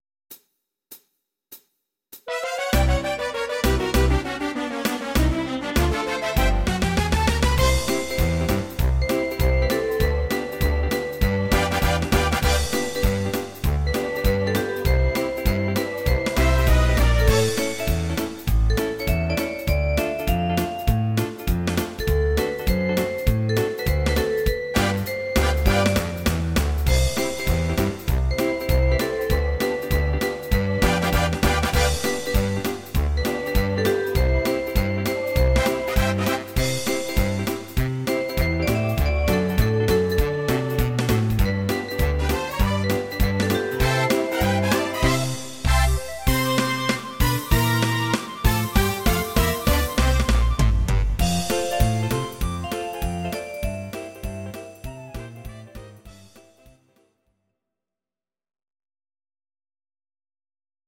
These are MP3 versions of our MIDI file catalogue.
Please note: no vocals and no karaoke included.
Your-Mix: 1970s (4265)